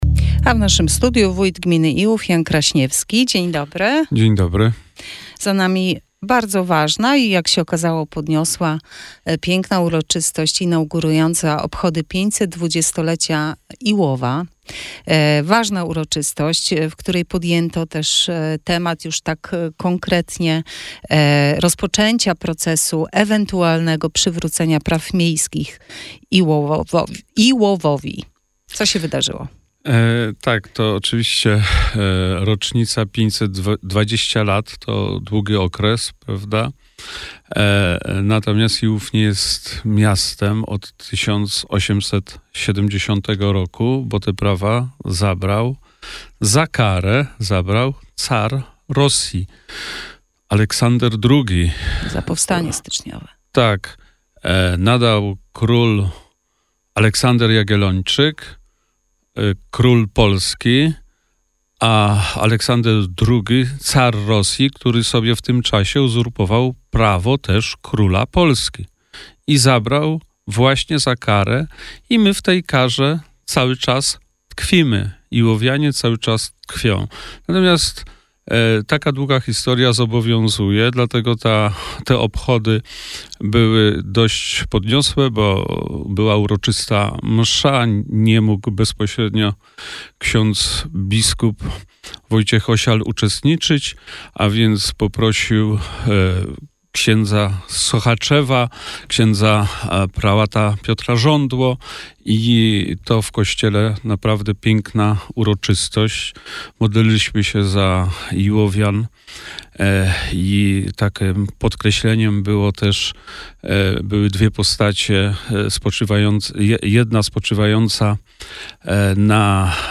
Wywiad z Wójtem Janem Kraśniewskim w Radio Sochaczew - Najnowsze - Gmina Iłów